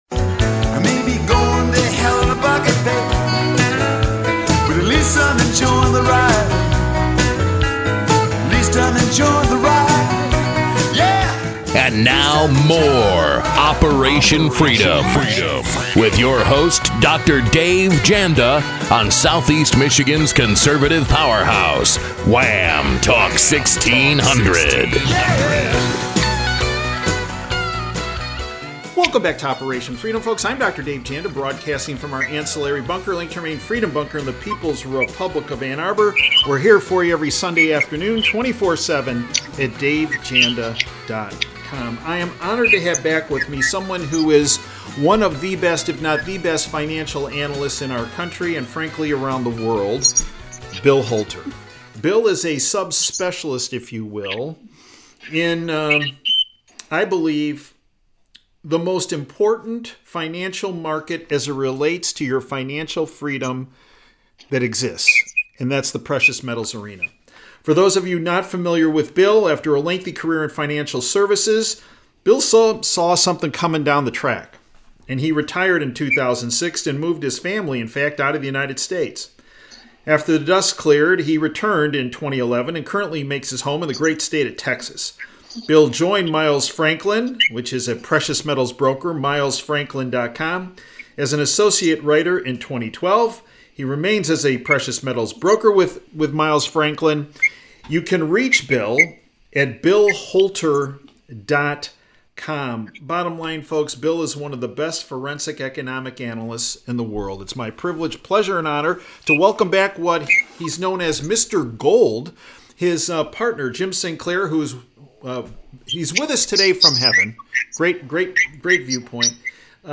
(Also posted under Interviews)